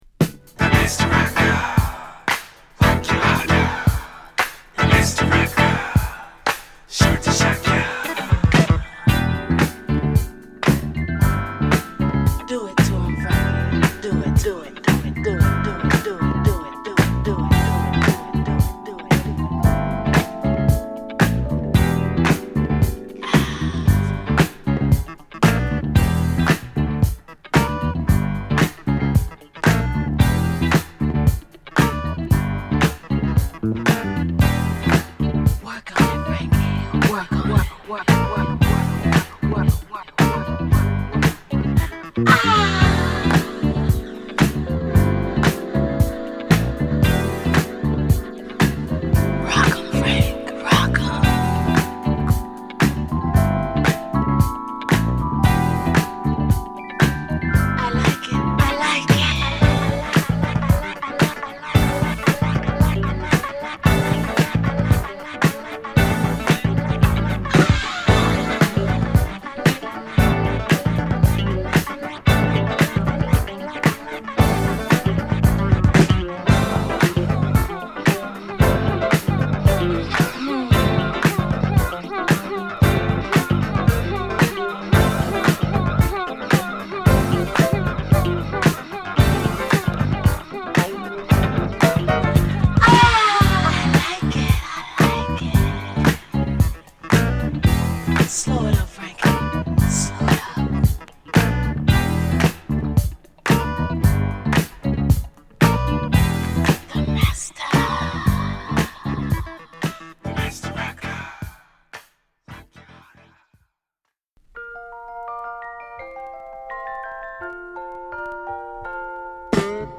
アーバンブギーな曲からメロウチューンまで素晴らしい1枚！